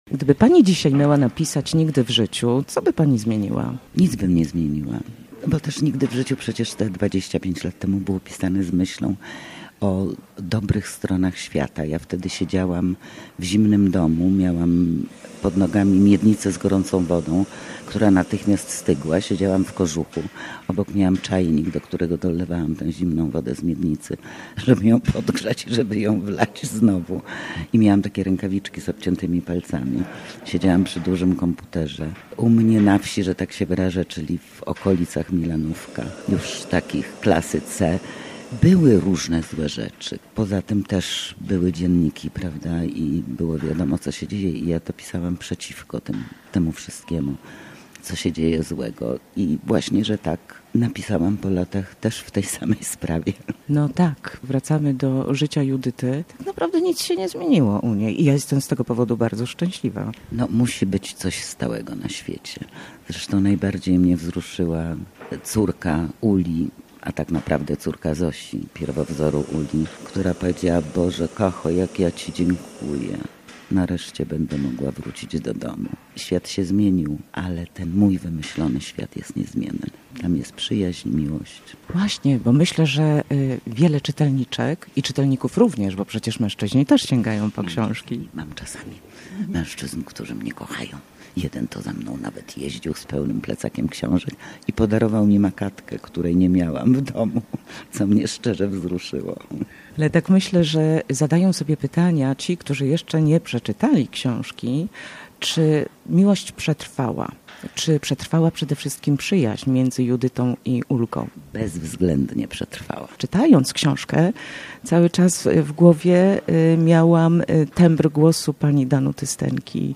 Przy kawie, podczas Targów Książki